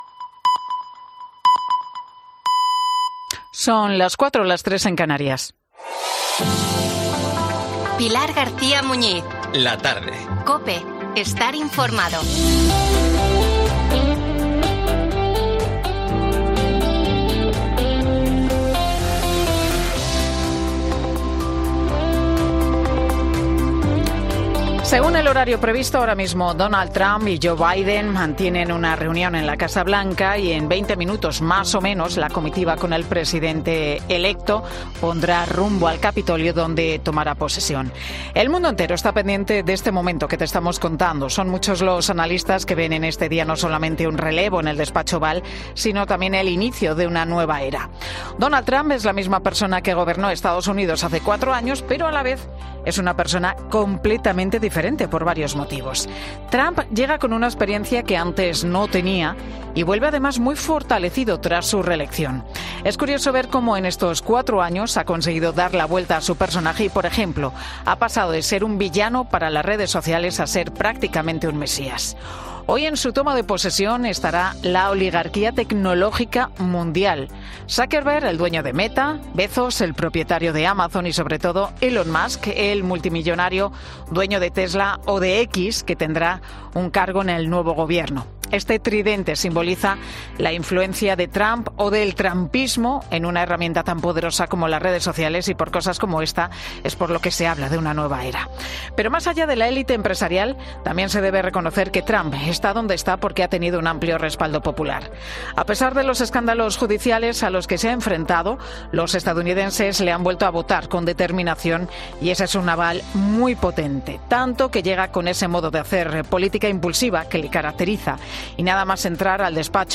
Ràdio Popular de Barcelona (COPE Barcelona)
Entreteniment